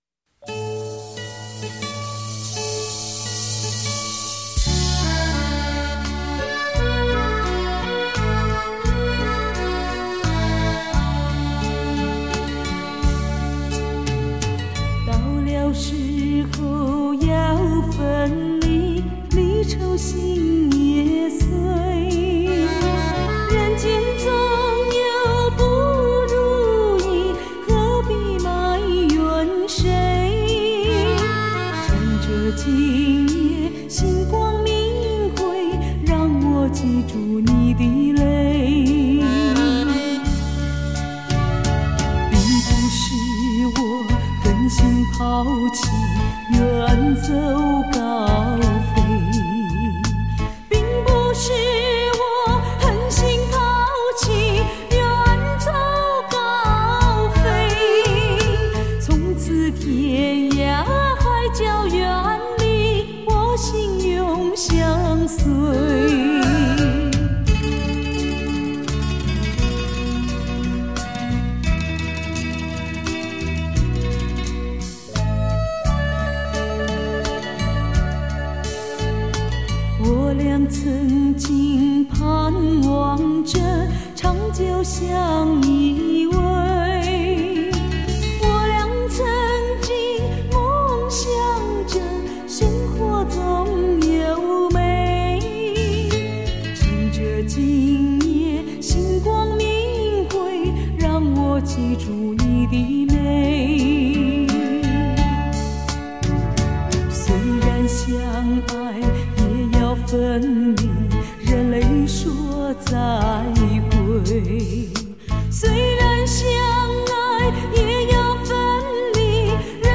舞厅规格
华尔滋歌唱版
将自己投入感性的歌声中